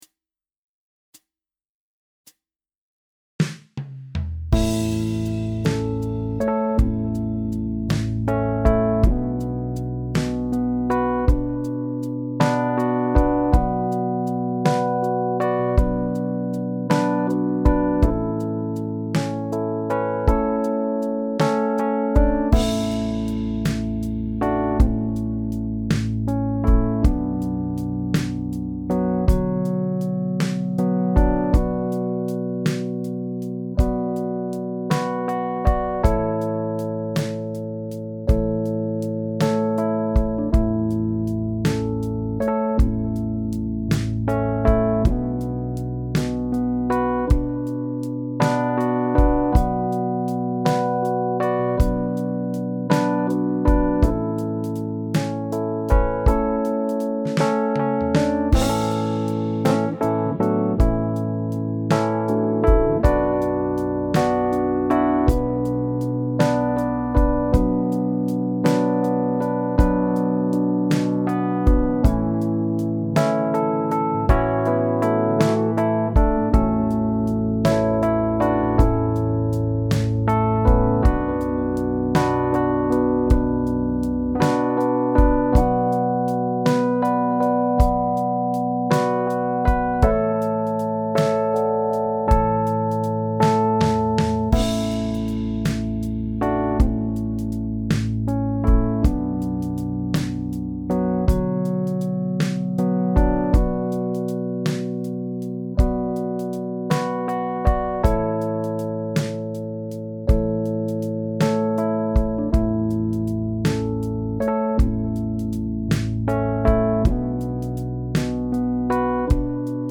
stripped down, melodic backing track